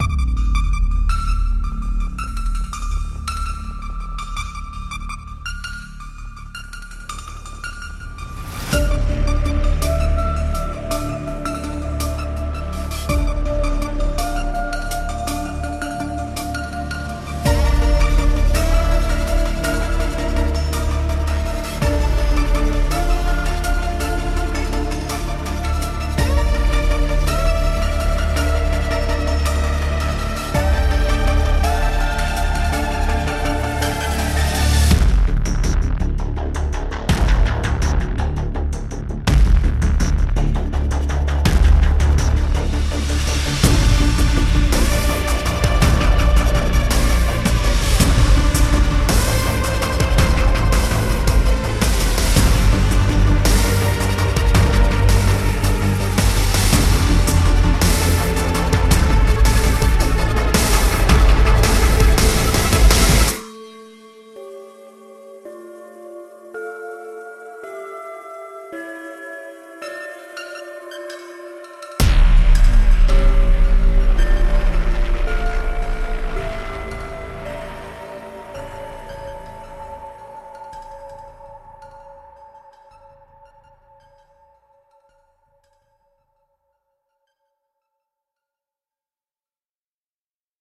Mosaic Leads探索了大型模拟合成器引线的电影潜力，将这一系列值得陈述的合成器提升到现代配乐领域。
电影合成器
无论是美女还是野兽，Heavyocity 的马赛克引线 Kontakt VST 提供一系列郁郁葱葱且激进的引线源，旨在与其有机攻击库和独特的声音设计元素相结合。
模拟主音
Mosaic Leads延续了Mosaic系列的工作，具有复杂的电影声音集合，完全围绕我们发现的大型模拟合成器线索的大小和规模而构建。